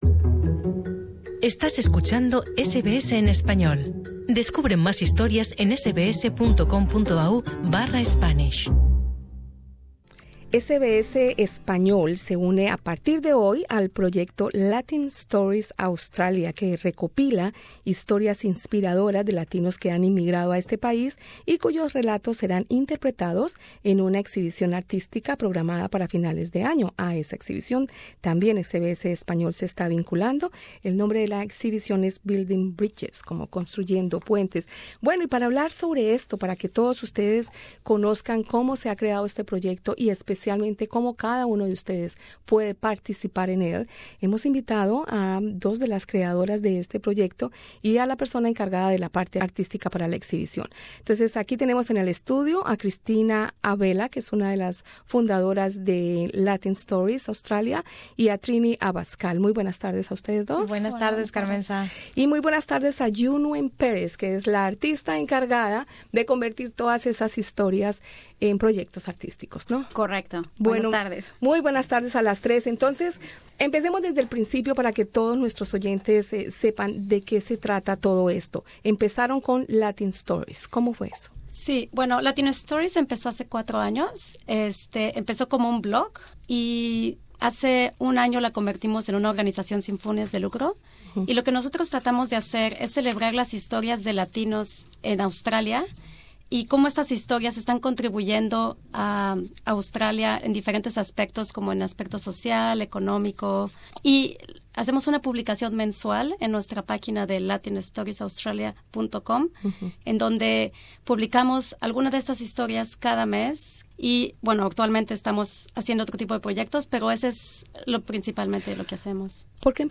en los estudios de SBS.
la entrevista